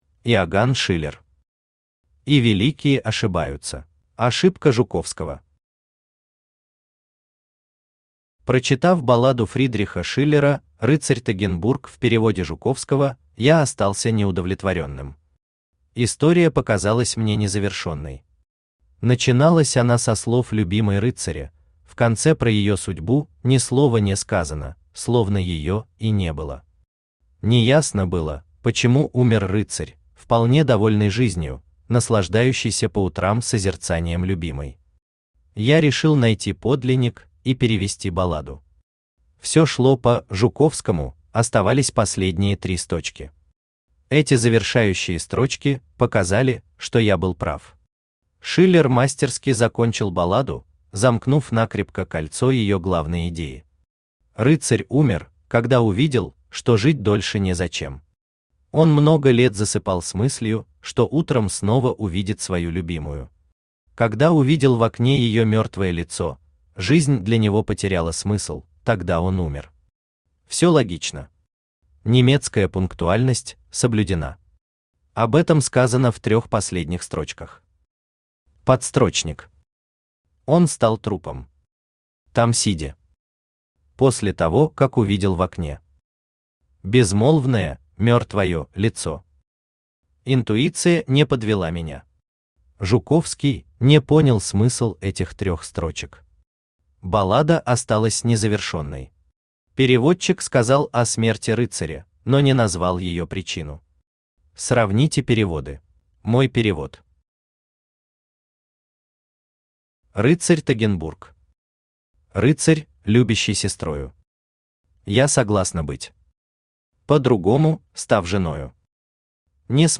Аудиокнига И великие ошибаются | Библиотека аудиокниг
Aудиокнига И великие ошибаются Автор Иоган Фридрих Шиллер Читает аудиокнигу Авточтец ЛитРес.